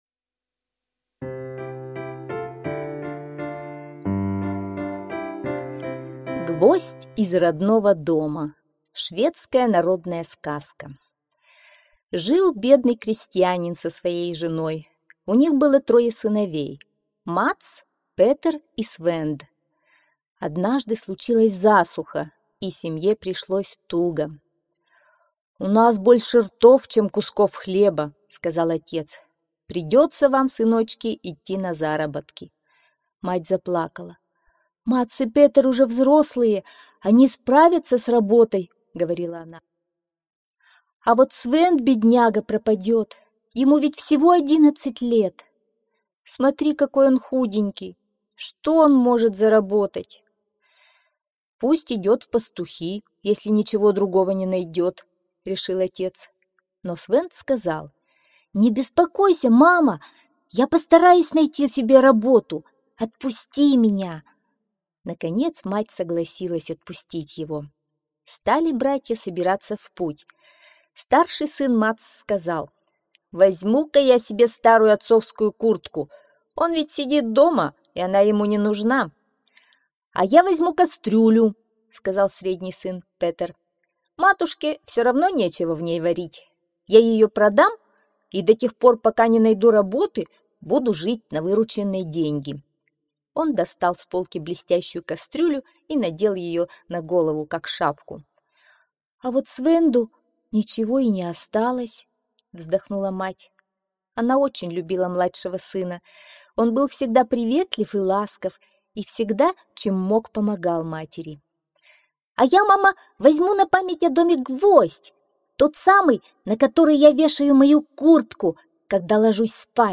Гвоздь из родного дома - шведская аудиосказка - слушать онлайн